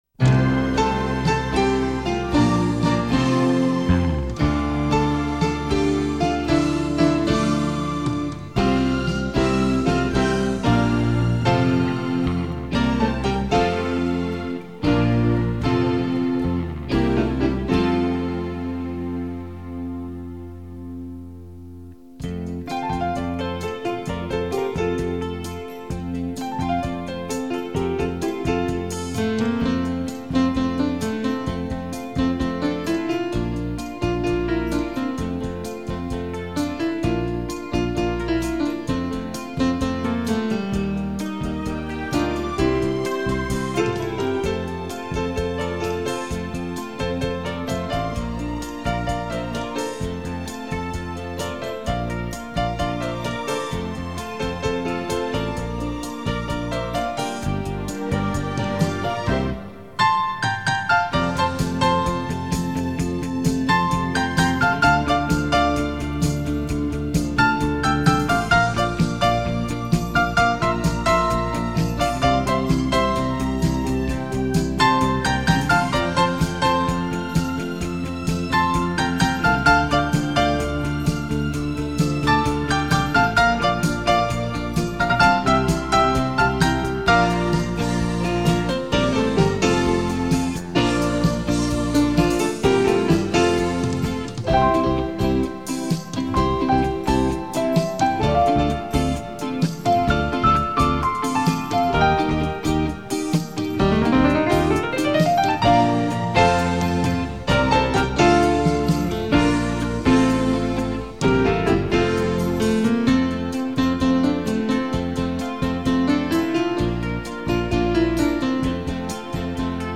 [2007-5-4]【夜之夜音】 守住這一片陽光 - 演奏曲